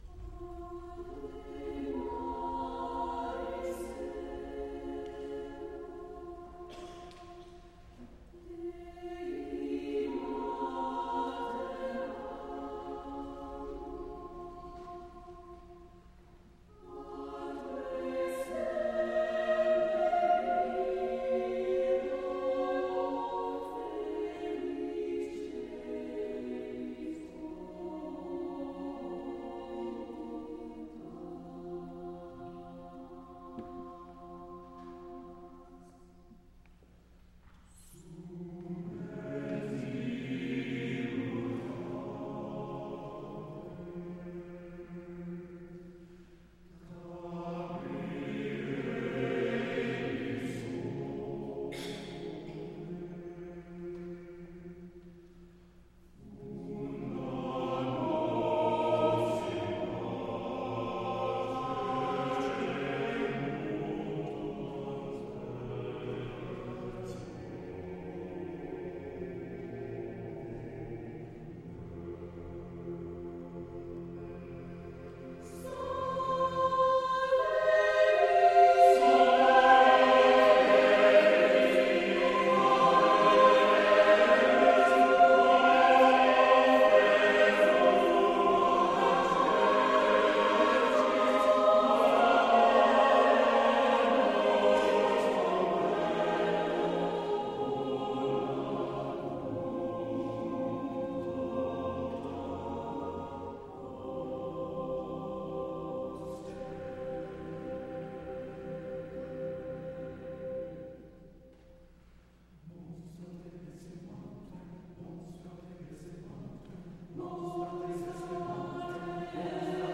SATB Double Choir
Voicing: SATB Double Choir